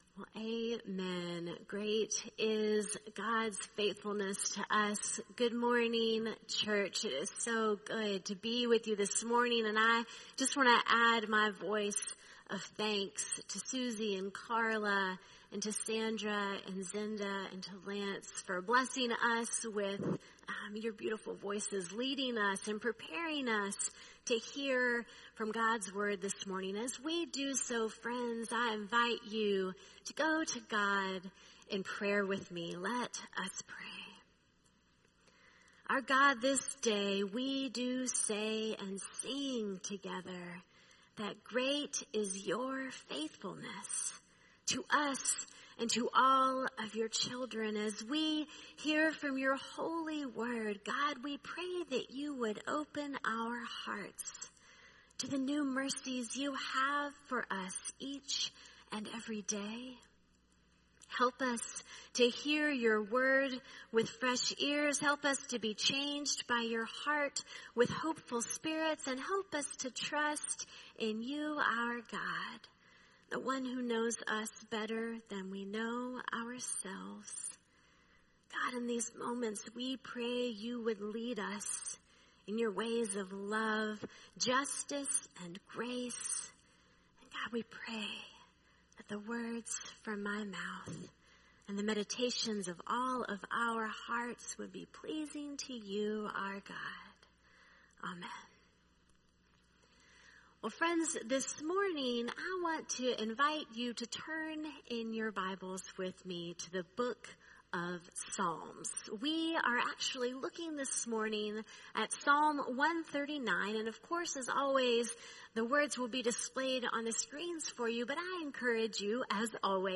sermon-719.mp3